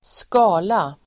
Uttal: [²sk'a:la]
skala.mp3